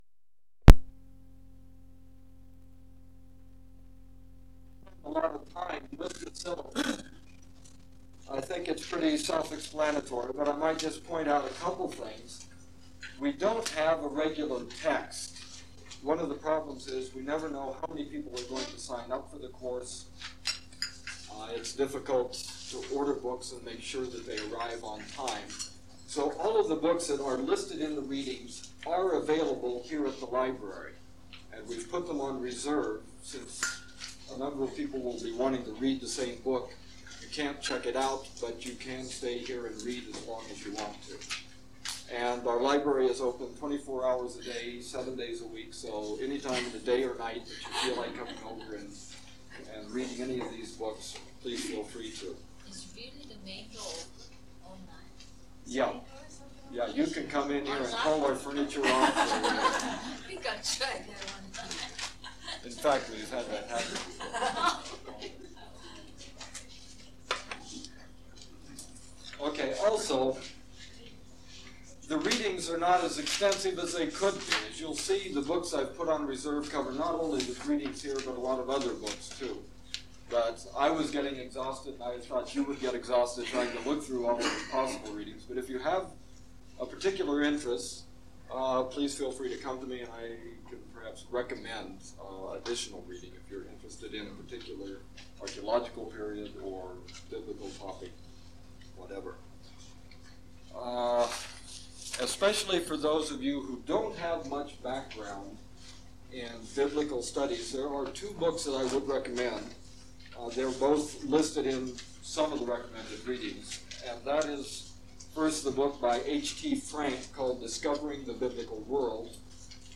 Archaeology of Jordan and Biblical History - Lecture 1: 1) Introduction 2) Brief history of Palestinian Archaeology 3) Explanation of excavation and survey techniques 4) Biblical Archaeology vs. Palestinian Archaeology
Format en audiocassette ID from Starchive 417967 Tag en Excavations (Archaeology) -- Jordan en Bible -- Antiquities en Archaeology Item sets ACOR Audio-visual Collection Media Arch_Bible_01_access.mp3